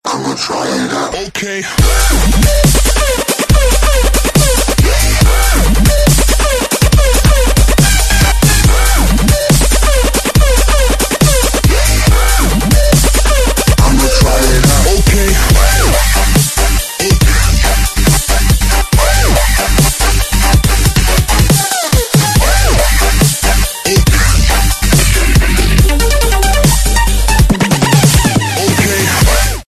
Категория: Игровые Рингтоны